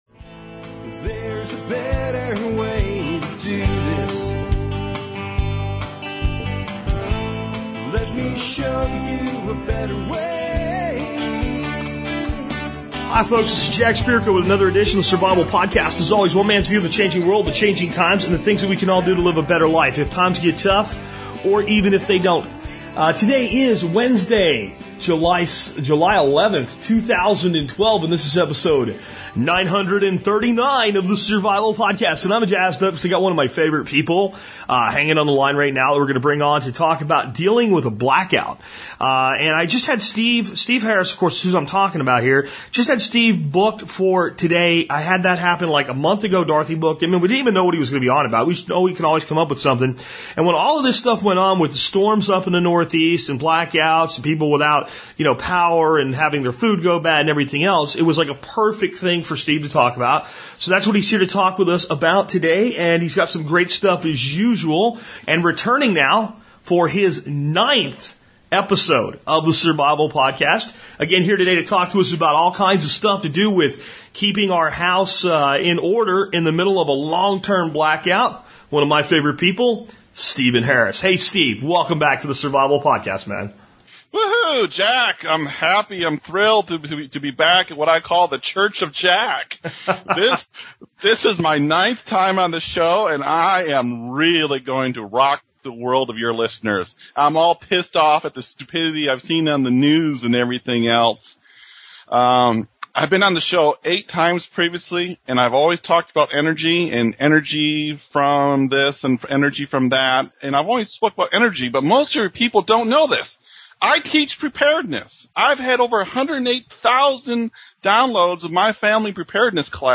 Generator Show Part 1 - How to Pick a Generator This show is on EVERYTHING Generators For Your Home.